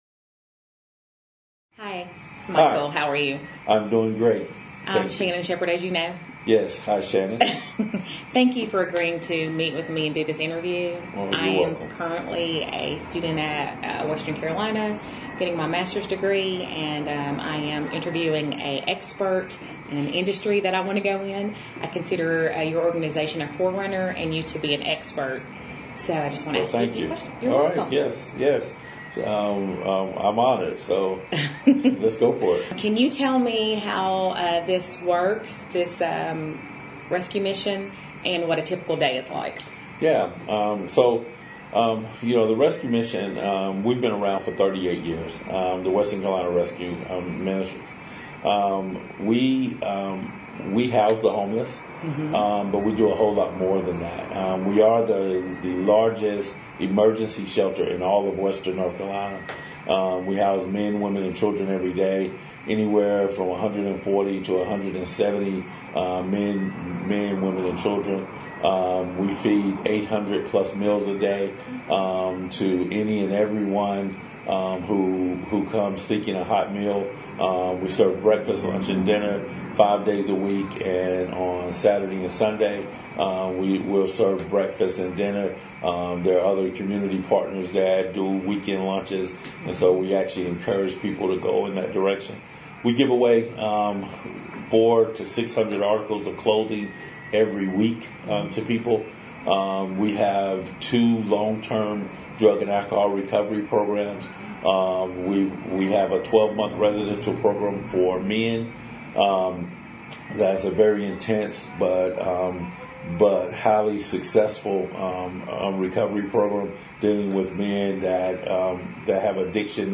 SME Interview